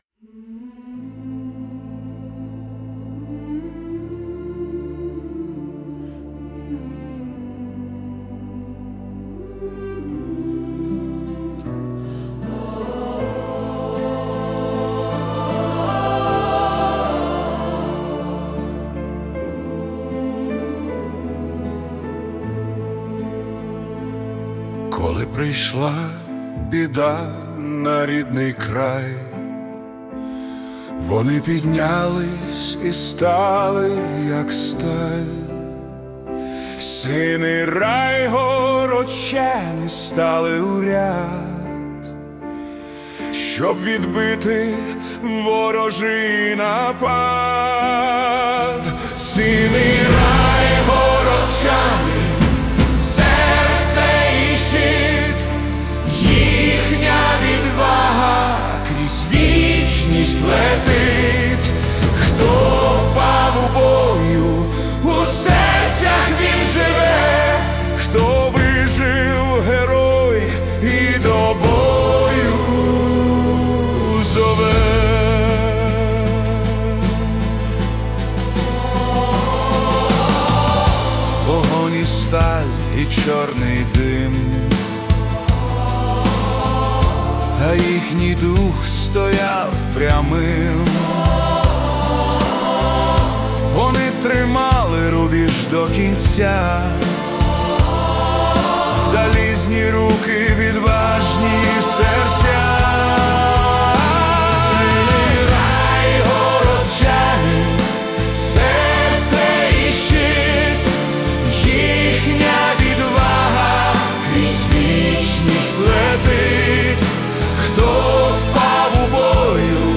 Epic Ballad / Cinematic Folk Rock
це монументальна епічна балада (78 BPM)
Фінал композиції звучить як молитва і ствердження життя.